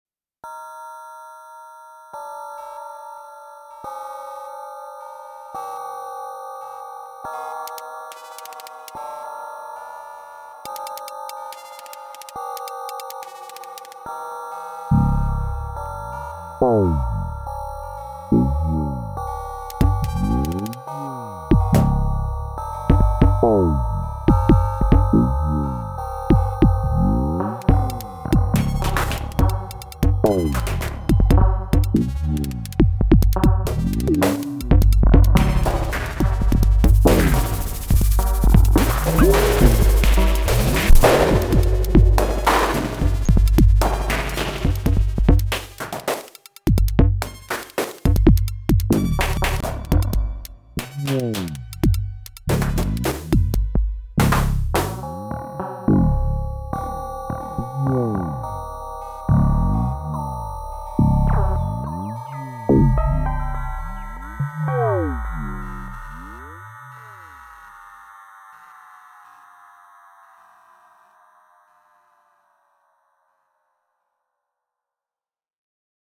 Matriarch + Blackbox drums hitting the SiX compressors hard